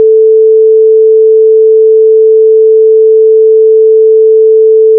测试音 " 440赫兹正弦波干音
描述：440Hz的正弦波测试音。